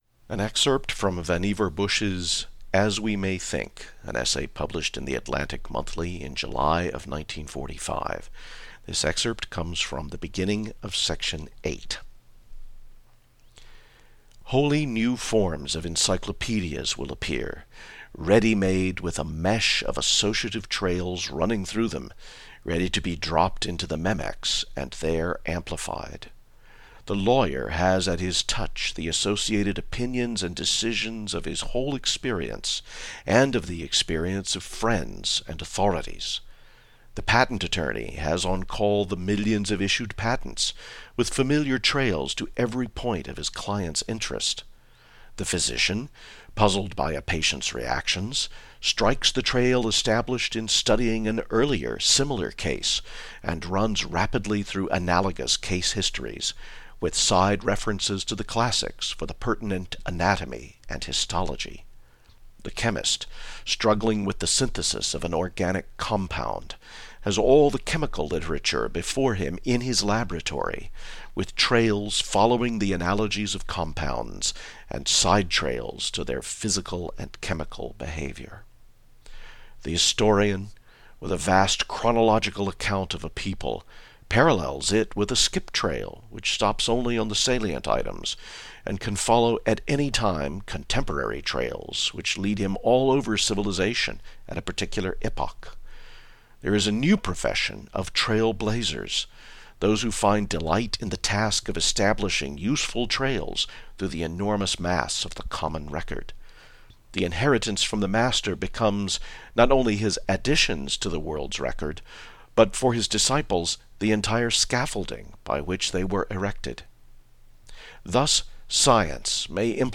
It’s made for oral interpretation.